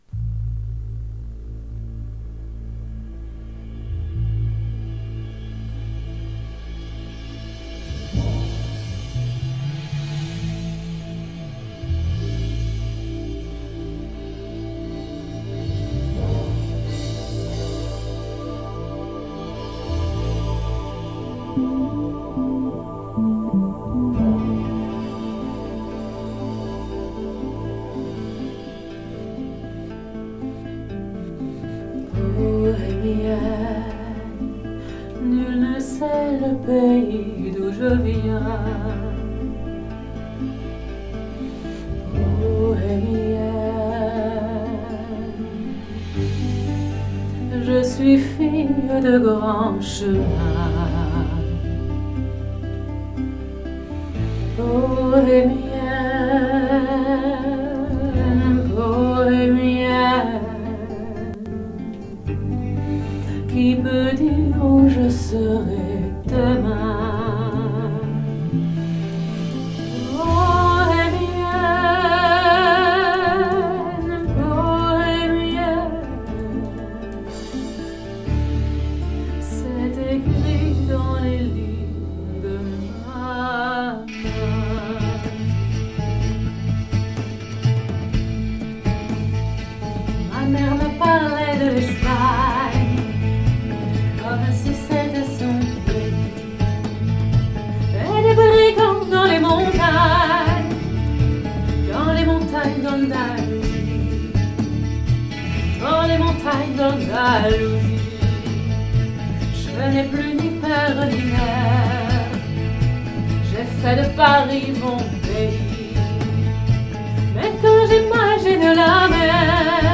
Opera Thaïs
Bandes-son
Artiste Lyrique